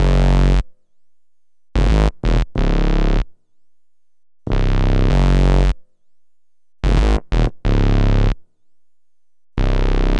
Ridin_ Dubs - Arp Bass.wav